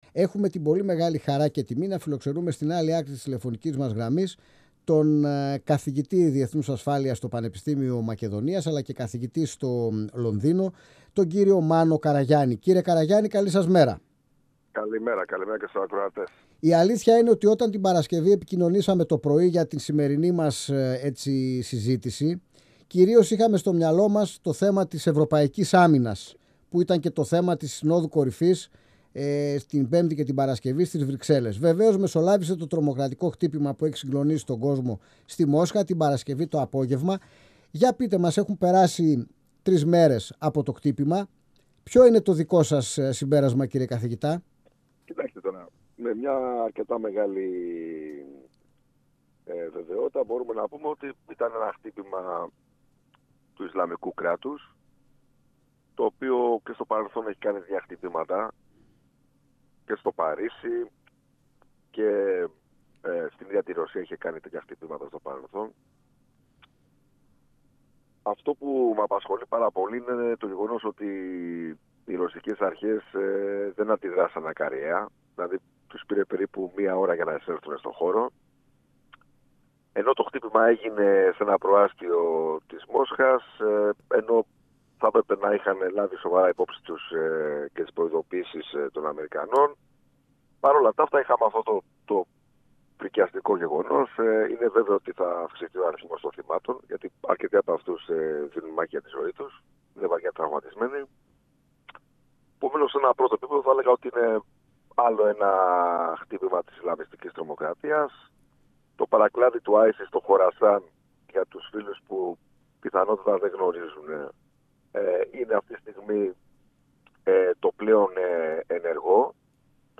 μιλώντας στην εκπομπή «Αίθουσα Σύνταξης» του 102FM της ΕΡΤ3.